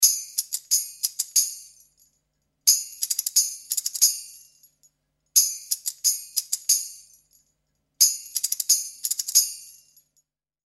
Звуки бубна
Играем мелодию на бубне